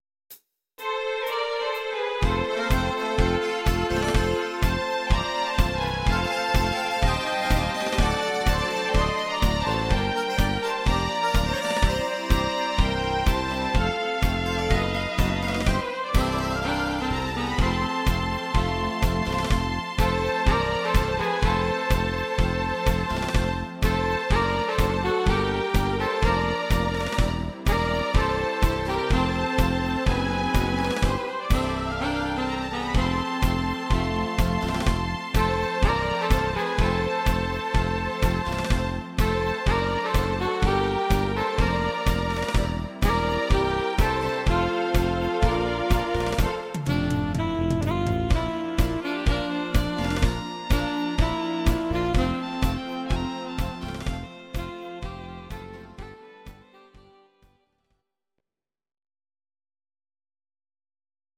These are MP3 versions of our MIDI file catalogue.
Please note: no vocals and no karaoke included.
Tango version